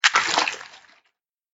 دانلود آهنگ قایق 1 از افکت صوتی حمل و نقل
دانلود صدای قایق 1 از ساعد نیوز با لینک مستقیم و کیفیت بالا
جلوه های صوتی